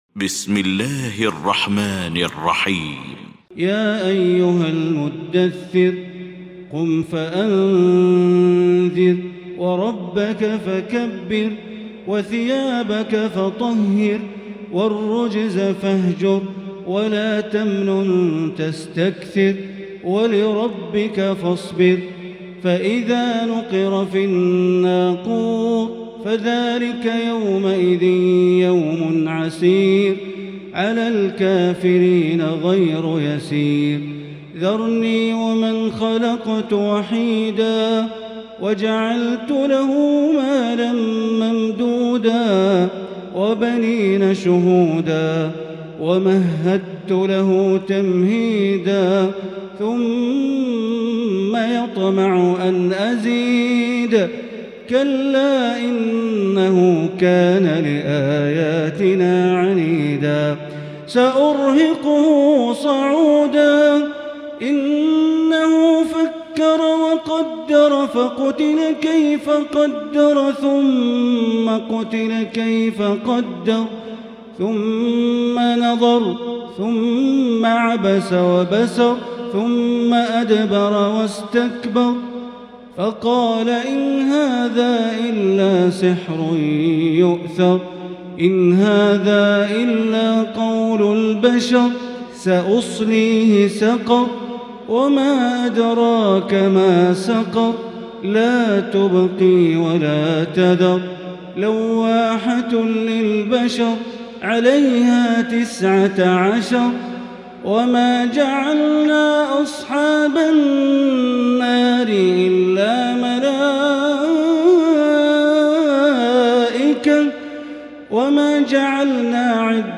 المكان: المسجد الحرام الشيخ: معالي الشيخ أ.د. بندر بليلة معالي الشيخ أ.د. بندر بليلة المدثر